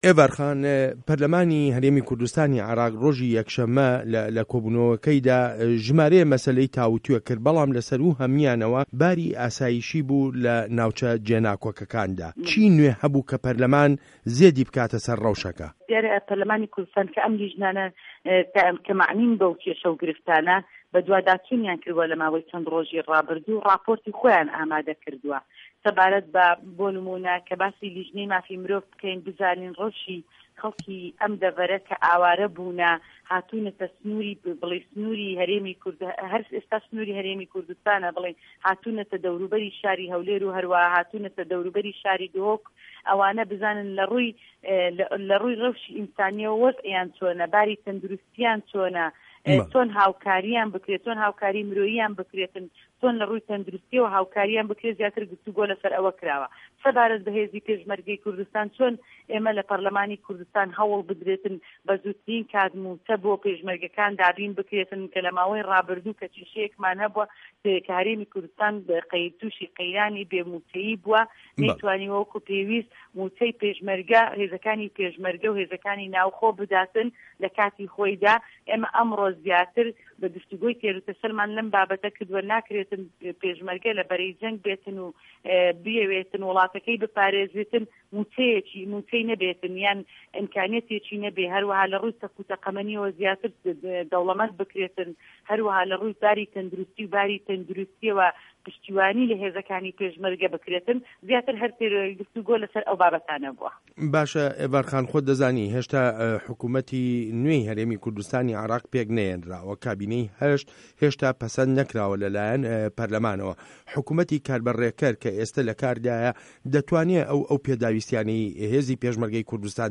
وتووێژ له‌گه‌ڵ ئیڤار ئیبراهیم